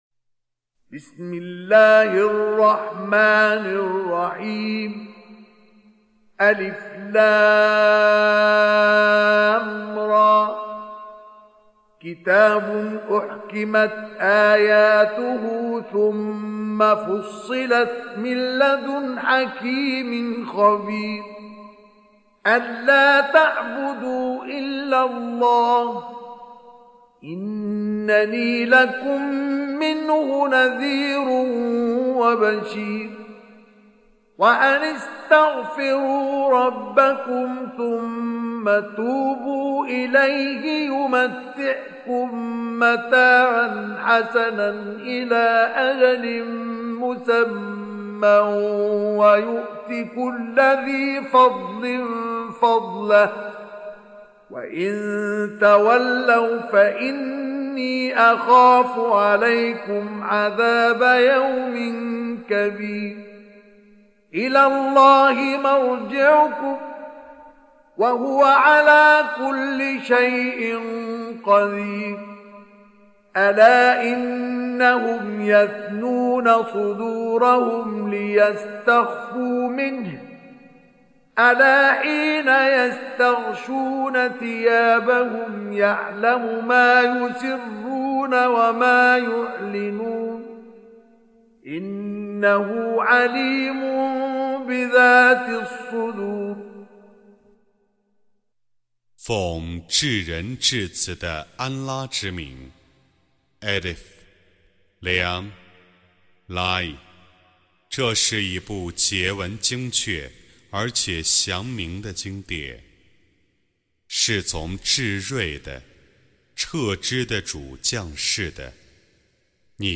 Recitation
Surah Sequence تتابع السورة Download Surah حمّل السورة Reciting Mutarjamah Translation Audio for 11. Surah H�d سورة هود N.B *Surah Includes Al-Basmalah Reciters Sequents تتابع التلاوات Reciters Repeats تكرار التلاوات